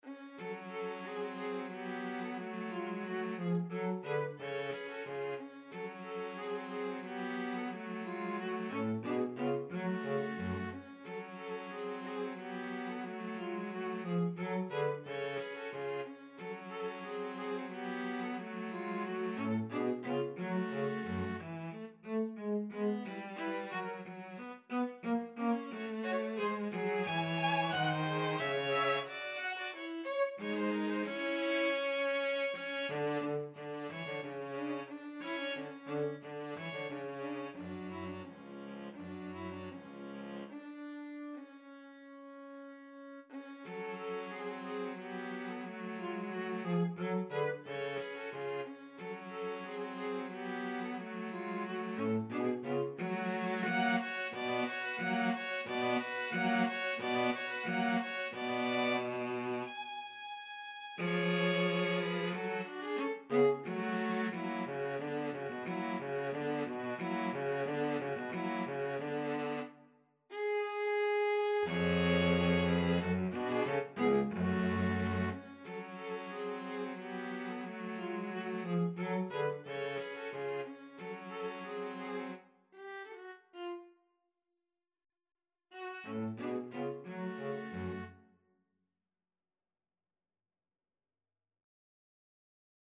ViolinViolaCello
2/4 (View more 2/4 Music)
Allegretto = 90
Classical (View more Classical String trio Music)